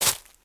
footsteps
decorative-grass-07.ogg